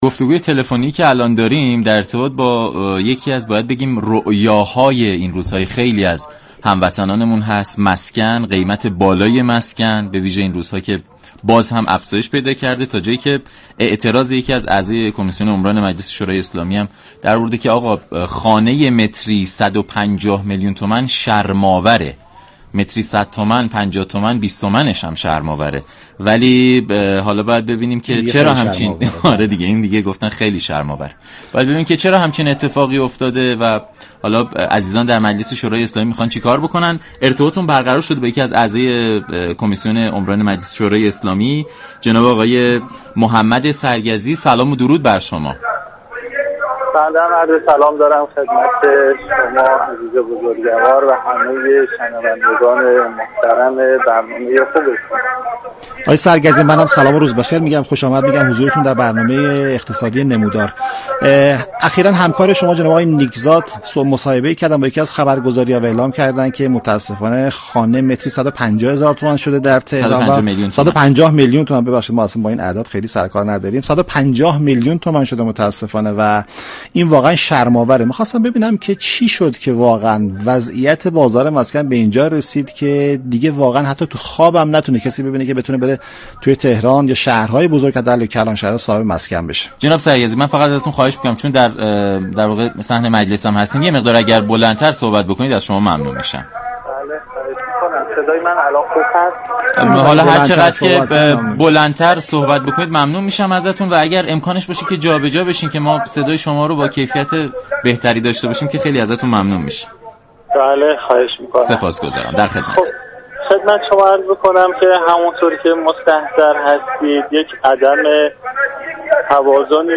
به گزارش شبكه رادیویی ایران، محمد سرگزی عضو كمیسیون عمران مجلس در برنامه نمودار درباره وضعیت بازار مسكن و افزایش بی سابقه قیمت آن گفت: بی توجهی ها و نبود برنامه ریزی مشخص طی سال های اخیر باعث شده قیمت مسكن در كلانشهری مثل تهران افزایش یابد.